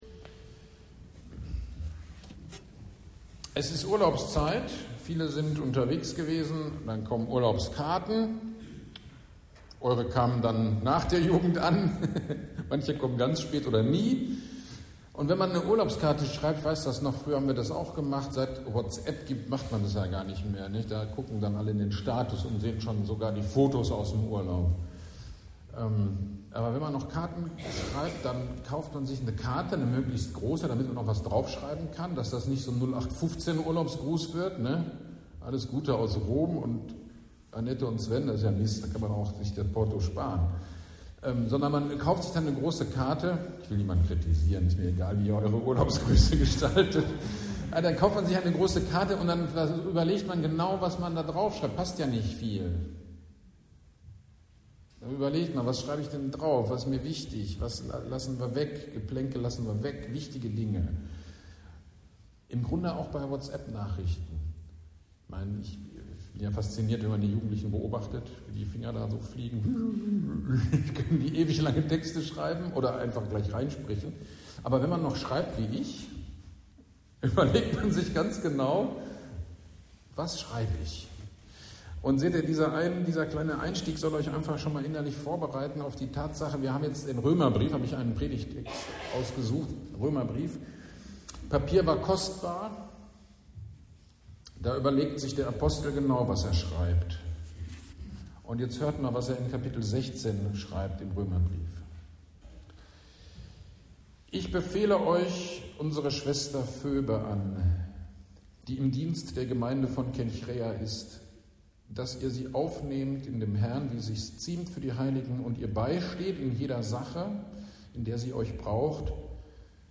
GD am 20.07.2025 Predigt zu Römer 16, 1-16